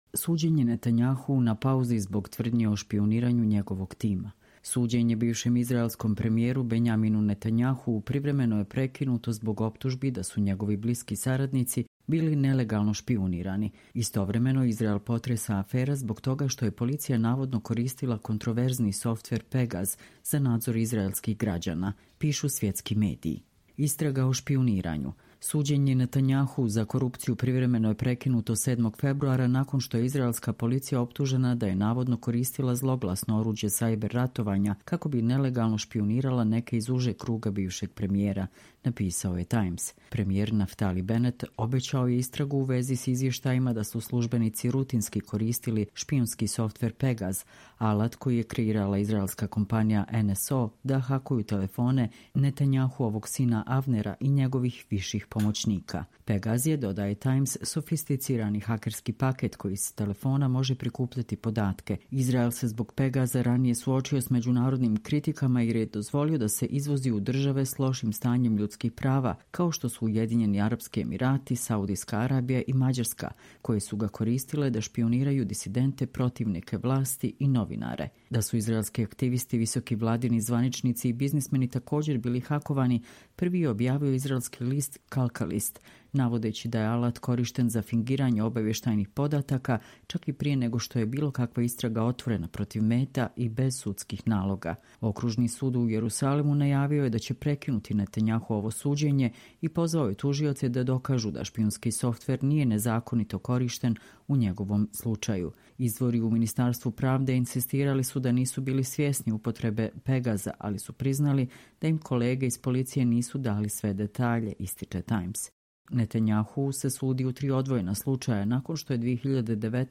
Čitamo vam: Suđenje Netanjahuu na pauzi zbog tvrdnji o špijuniranju njegovog tima